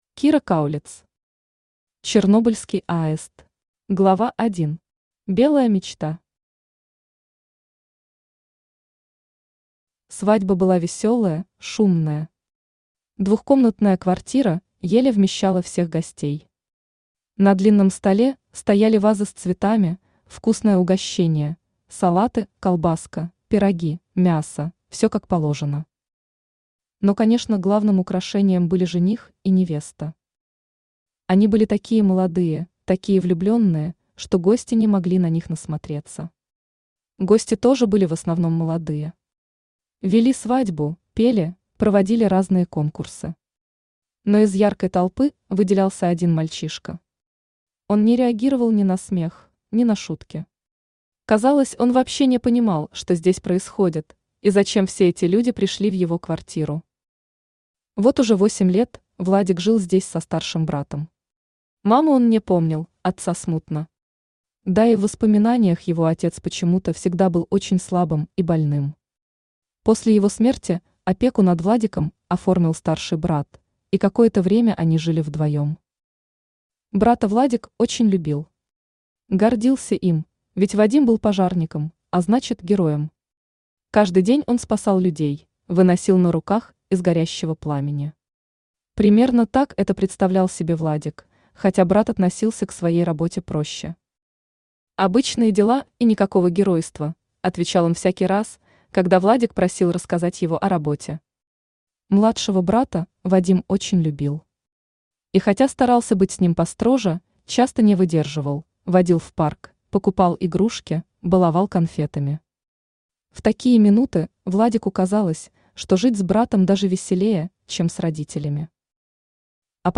Аудиокнига Чернобыльский аист | Библиотека аудиокниг
Aудиокнига Чернобыльский аист Автор Кира Каулиц Читает аудиокнигу Авточтец ЛитРес.